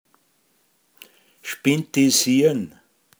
pinzgauer mundart
nachdenken spintisiern